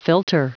Prononciation du mot filter en anglais (fichier audio)
Prononciation du mot : filter